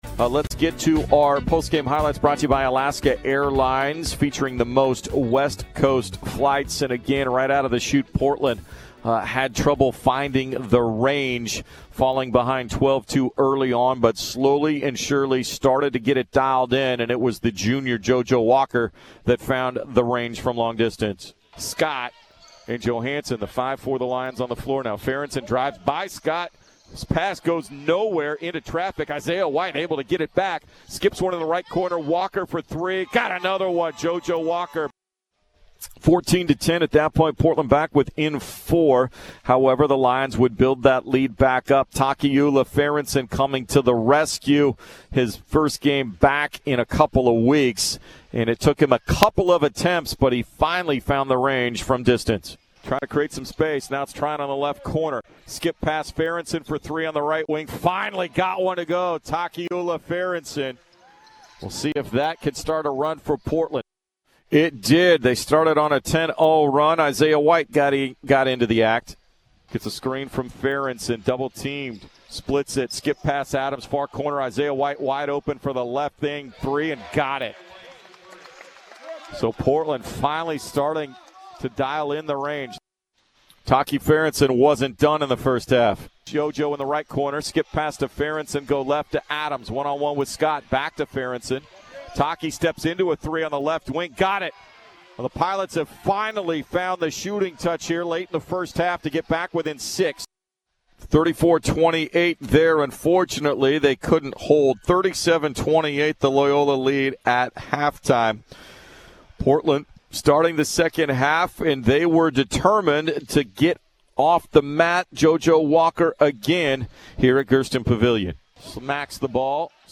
Men's Hoops Post-Game Highlights at LMU
January 23, 2020 Portland dropped a 77-65 men's basketball game at LMU on Jan. 23, 2020. Hear the highlights and post-game recap from 910 ESPN-Portland.